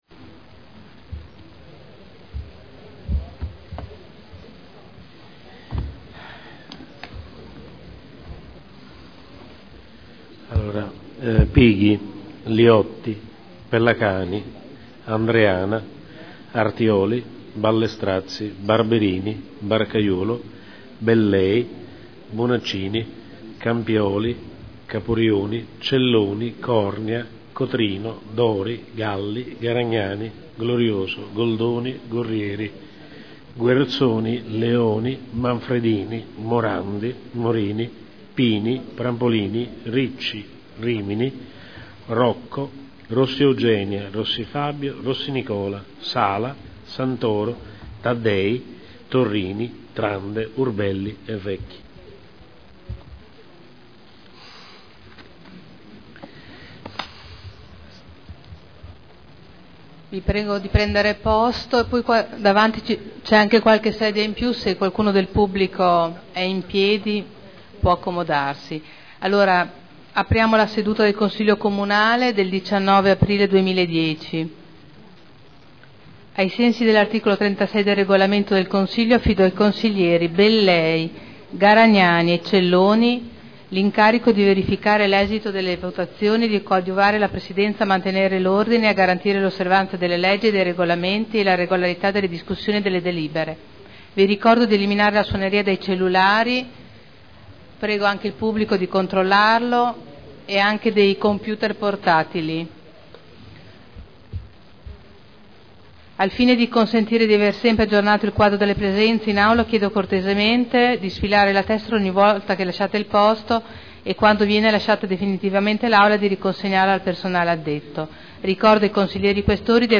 Presidente — Sito Audio Consiglio Comunale
Appello ed apertura del Consiglio Comunale